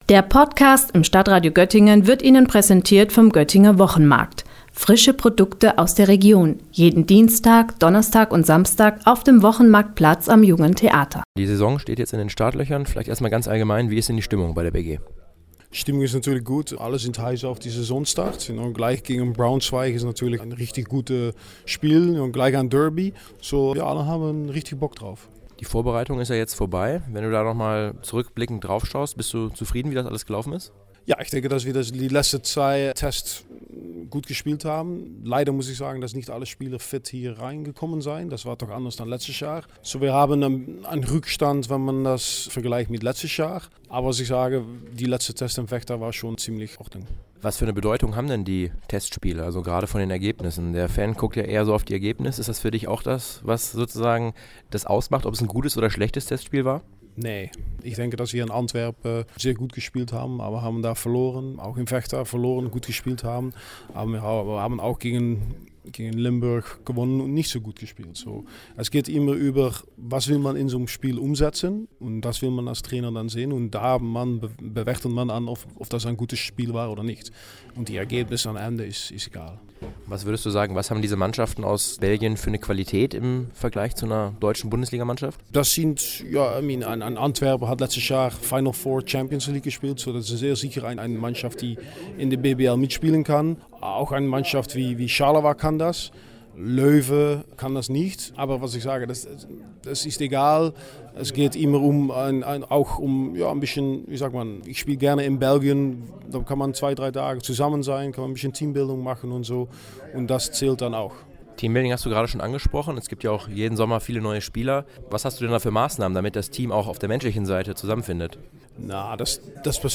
StadtRadio-Interview